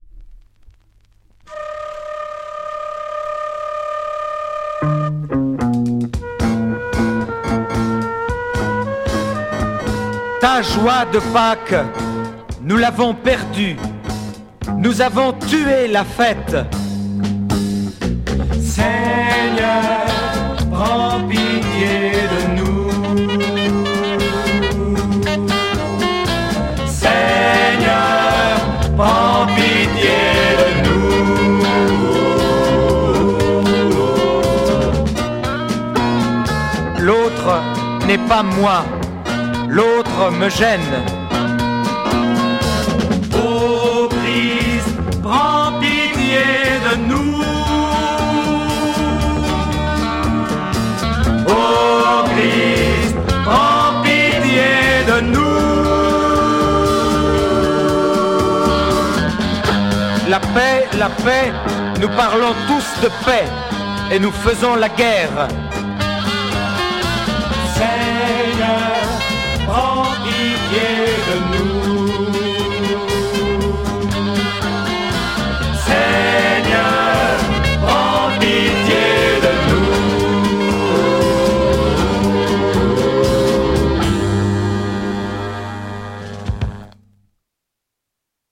Private French psych Xian EP